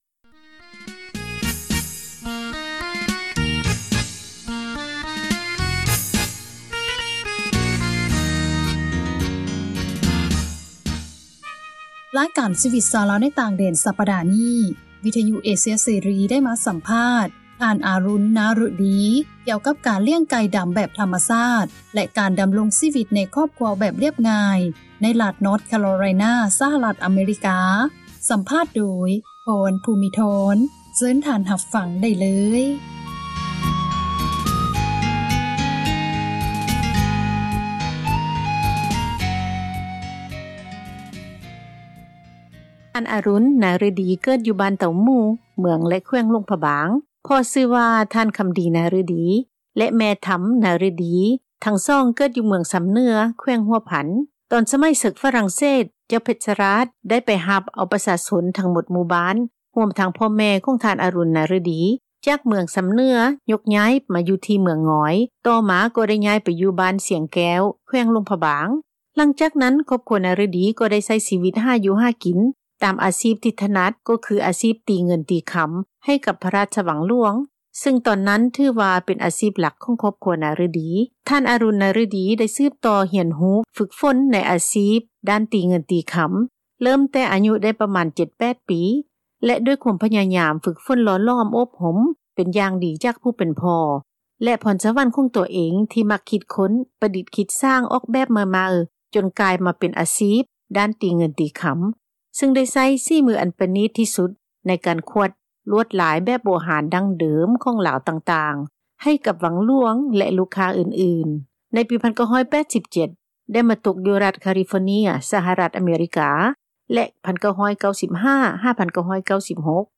ໄດ້ມາສັມພາດ